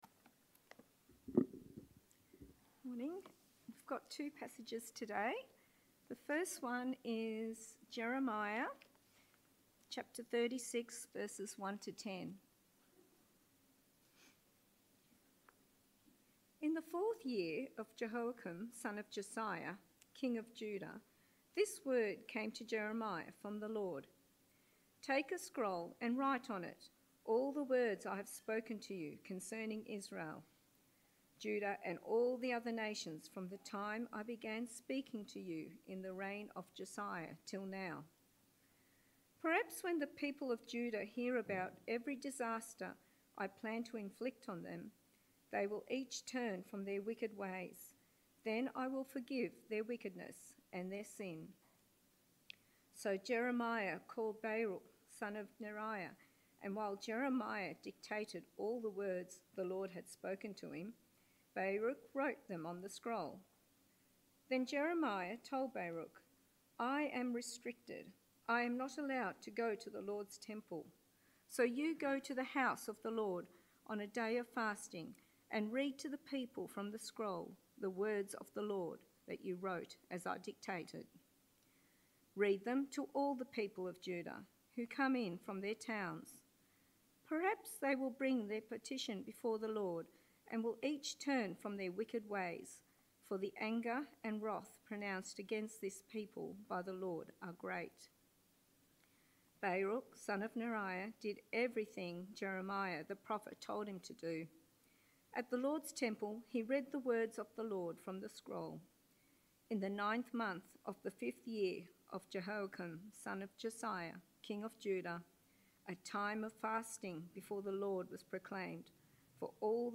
Sermons | Dickson Baptist Church